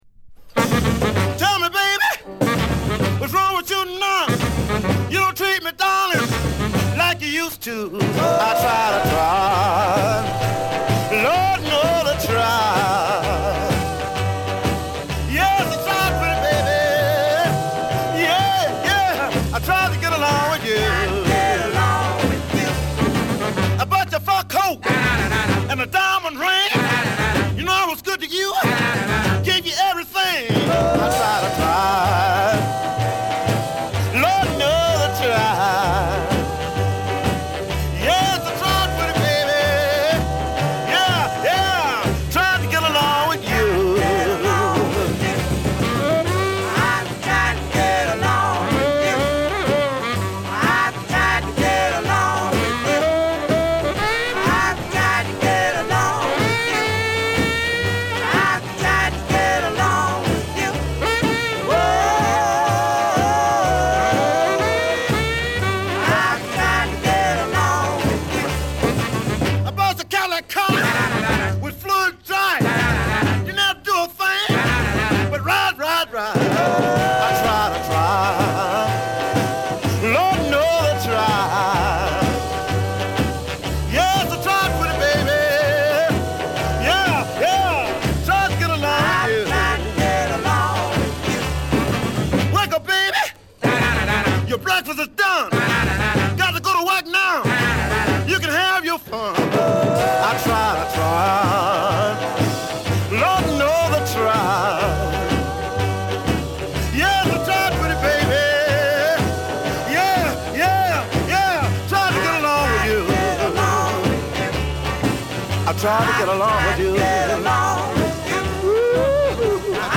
文句なしのスーパー・ストロングR&Bを叩きつける。